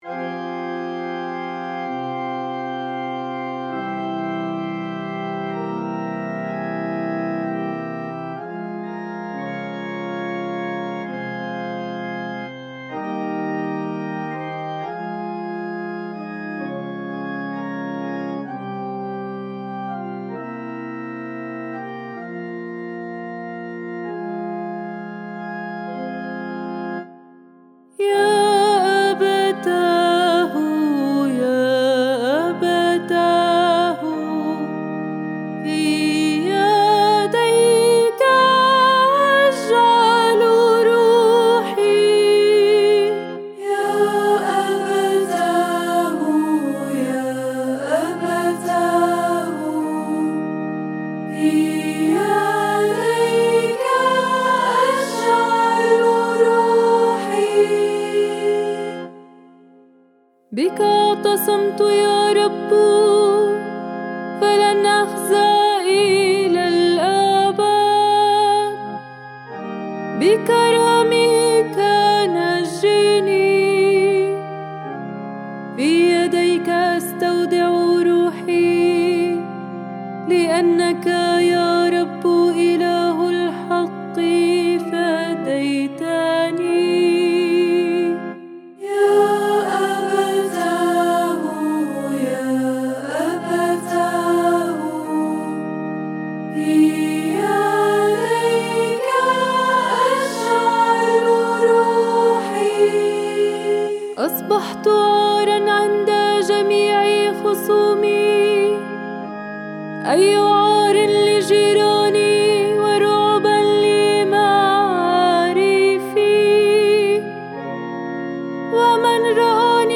مزمور الردّة ليوم الجمعة العظيمة (اللحن الثاني) (ك. الأسبوع المقدس-ص 241)